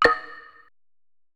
SNARE [Kanye].wav